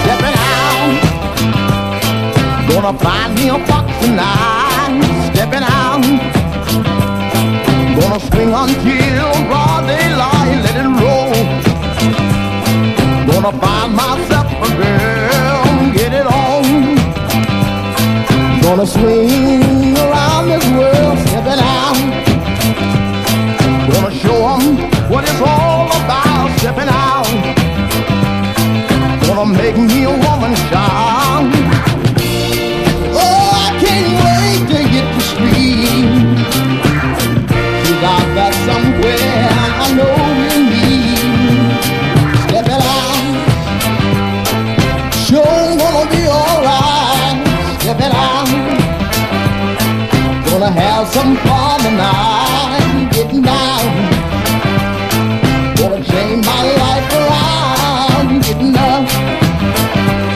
ROCK / 60'S / CHRISTMAS / OLDIES
ガレージにソフトロック、ソウル、R&Bと何でもありな60'Sクリスマス・コンピレーション！